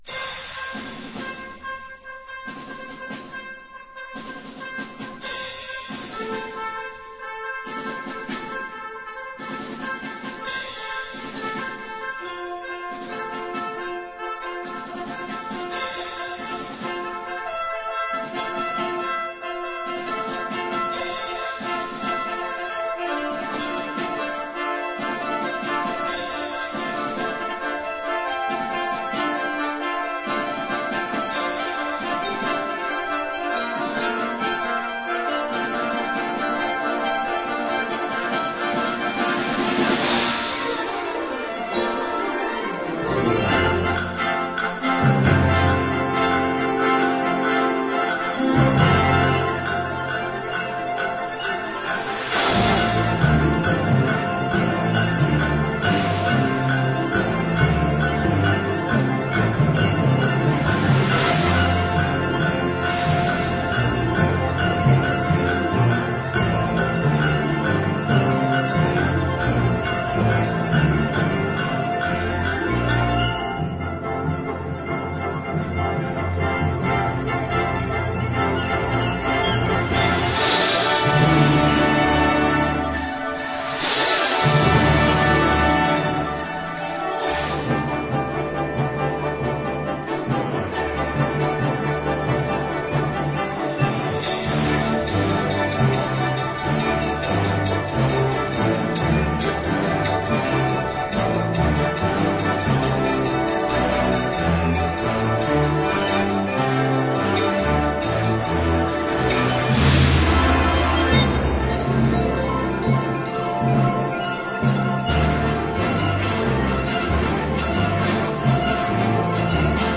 Catégorie Harmonie/Fanfare/Brass-band
Instrumentation Ha (orchestre d'harmonie)